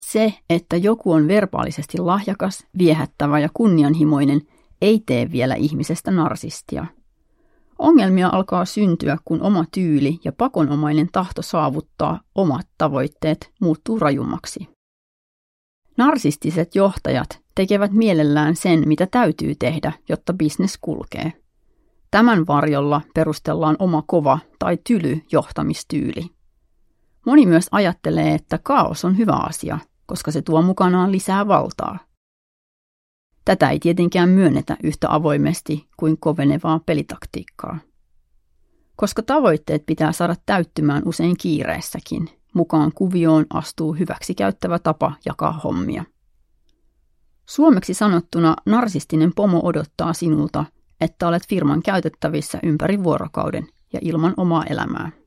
Tunnelman voima - Nouse uudelle tasolle: Näin kasvat, menestyt ja olet onnellinen – Ljudbok